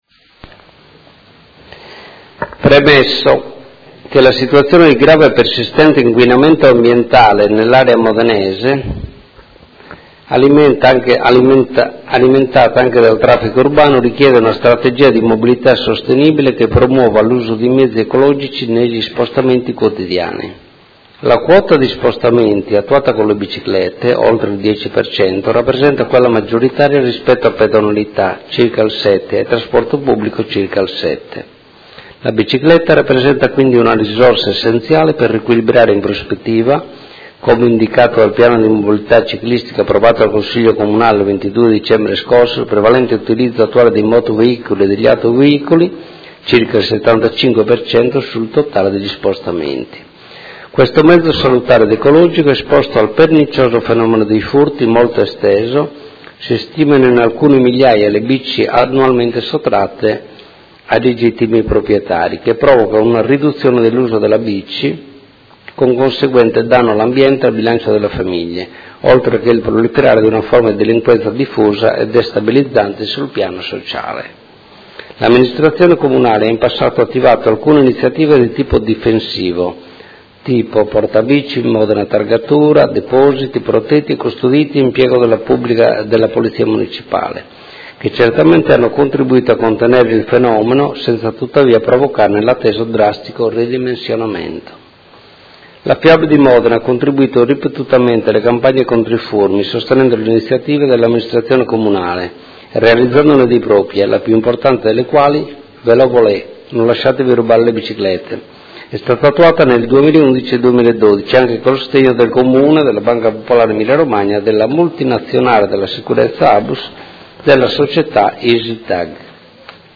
Marco Cugusi — Sito Audio Consiglio Comunale
Seduta del 15/06/2017. Ordine del Giorno presentato dal Gruppo Art.1-MDP, dai Consiglieri Chincarini e Campana (Per Me Modena) e dalla Consigliera Scardozzi (M5S) avente per oggetto: Iniziative di contrasto ai furti di bici